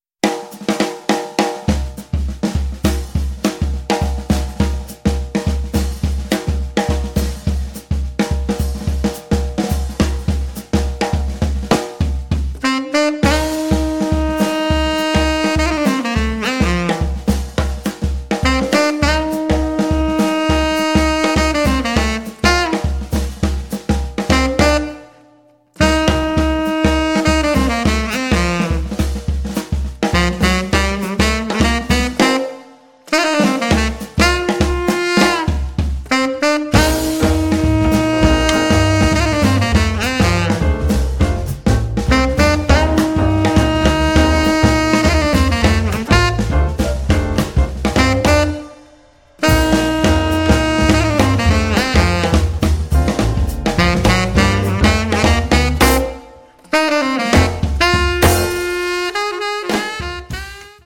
piano
sax tenore, soprano
contrabbasso
batteria